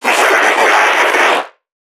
NPC_Creatures_Vocalisations_Infected [52].wav